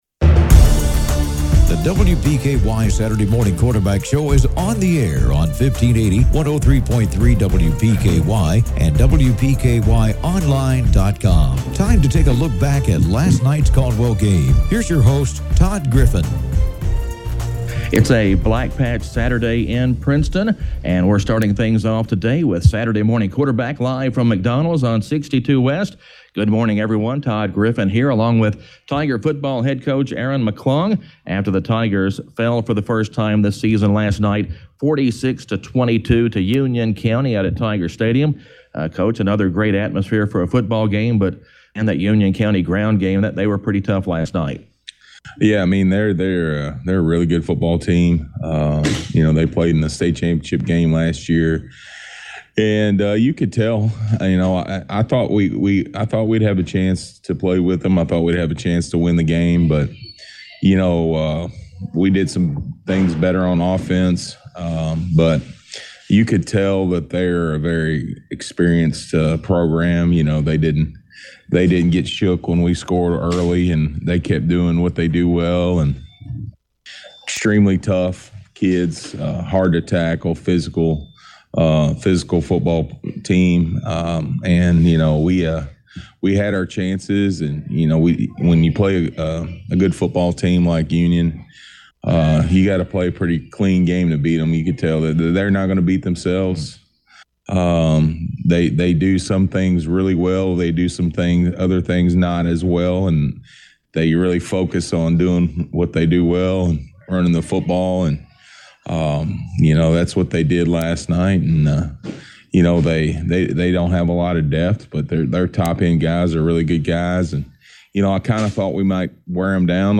from McDonald’s in Princeton